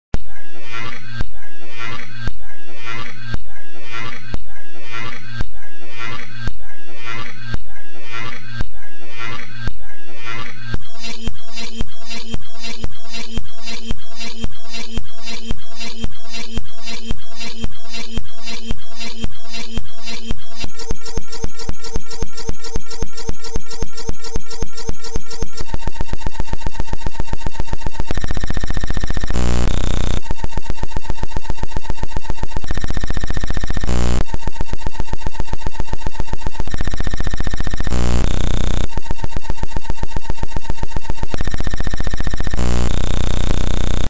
Tags: game show sounds